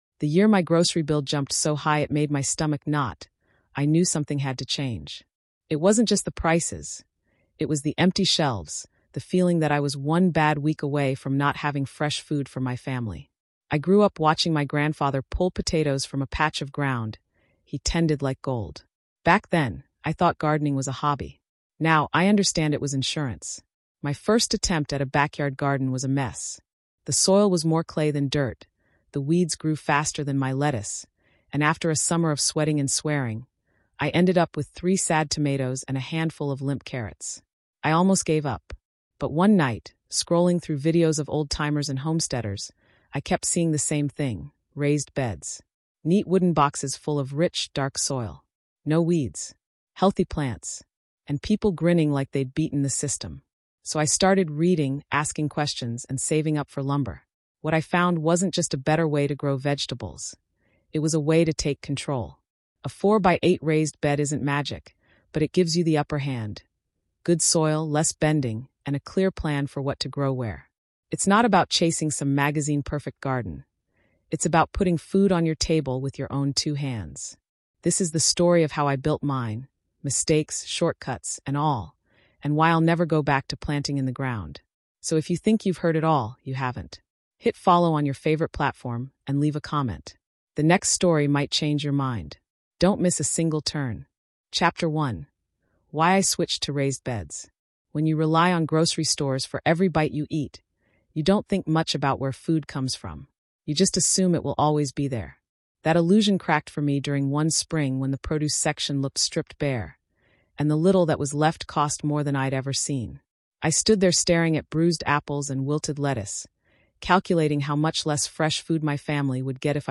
In this hands-on episode, a seasoned homesteading expert will walk you through the process of building a sturdy 4×8 raised bed, layering it with nutrient-rich soil, and planning effective crop rotation for sustainable living and year-round harvests. From selecting safe, affordable materials to harvesting your first tomatoes, every chapter blends practical instruction with personal experiences, making it feel like a neighbor is helping you grow your own food.